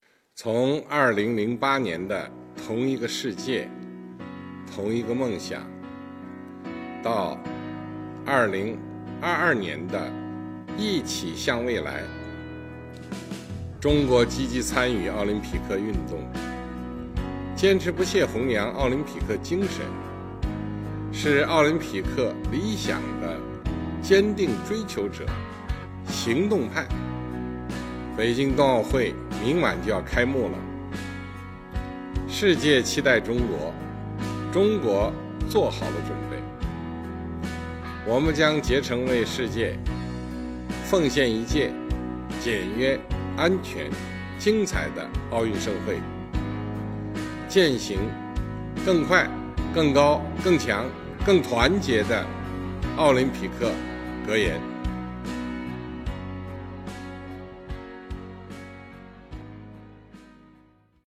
国家主席习近平2月3日向国际奥委会第139次全会开幕式发表视频致辞。